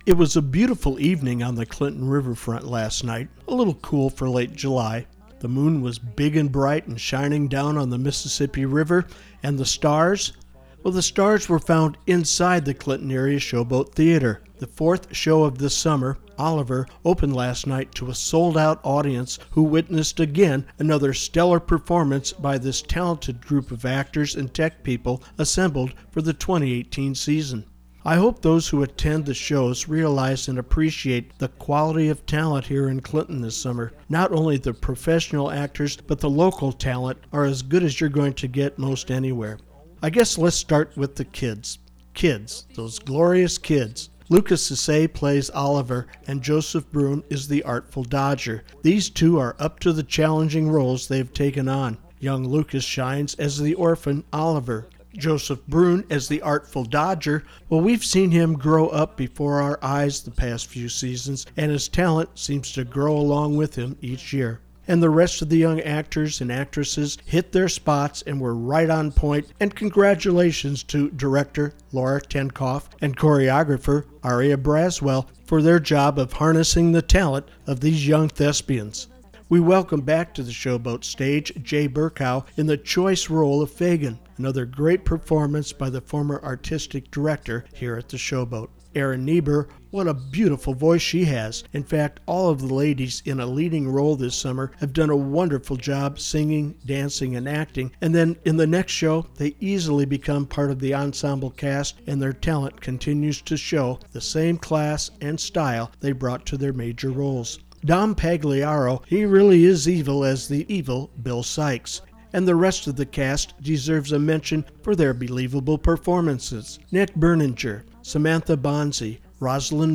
Download link: CAST Oliver Review